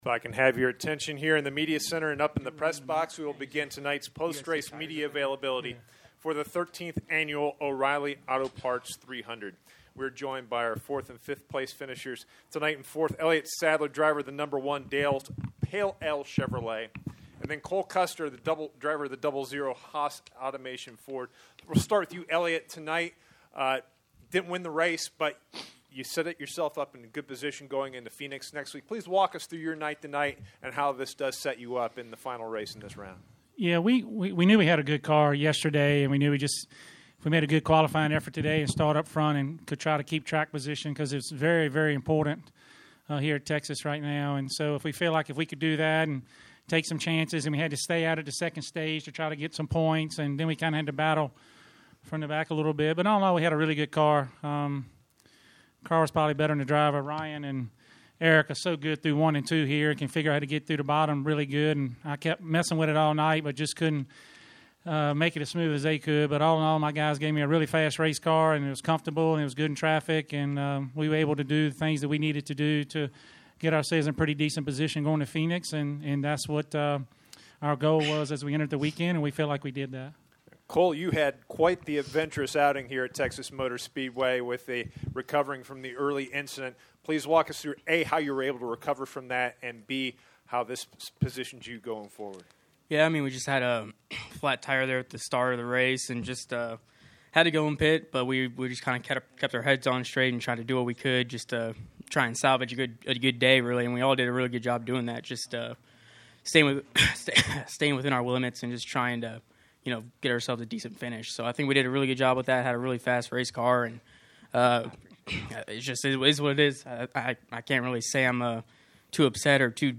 Media Center Interviews:
Second-place finisher Ryan Blaney, fourth-place finisher Elliott Sadler and fifth-place finisher Cole Custer –